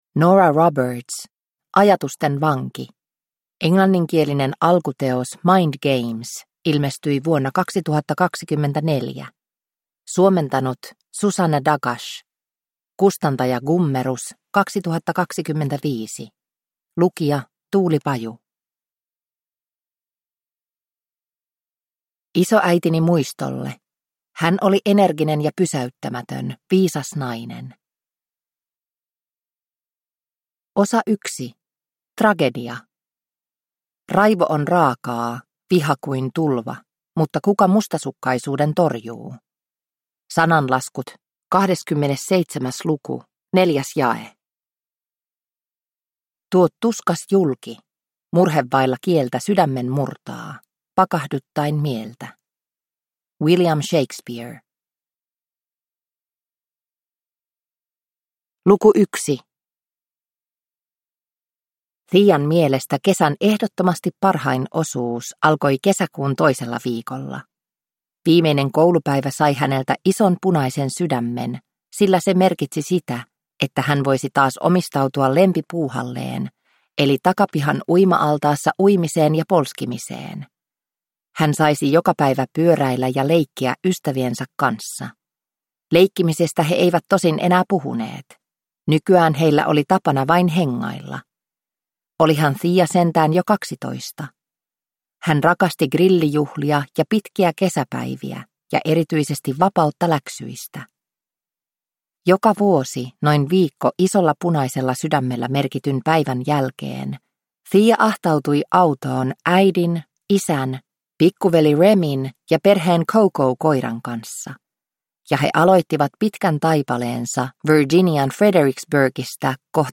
Ajatusten vanki – Ljudbok